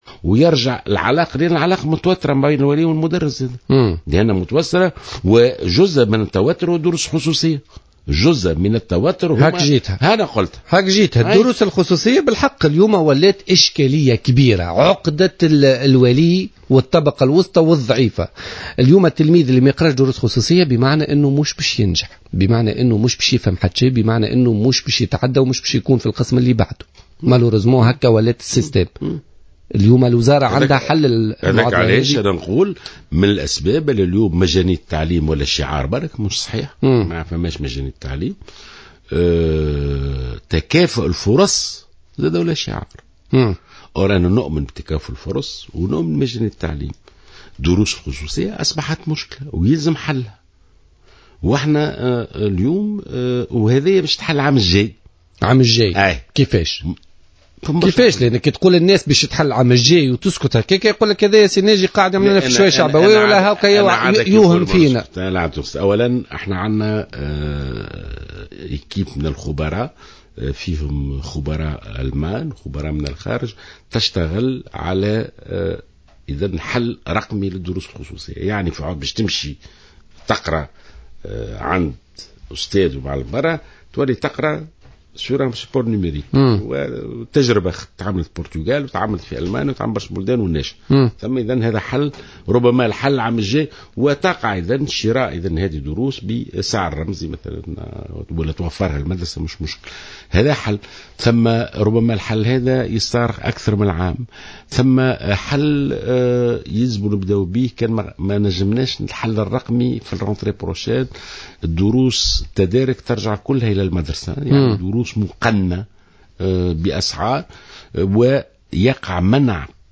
قال وزير التربية والتعليم ناجي جلول لبرنامج "بوليتيكا" على "جوهرة أف أم" اليوم الاثنين 18 ماي 2015 انه سيتم القضاء على ظاهرة الدروس الخصوصية في تونس بدءا من العام المقبل.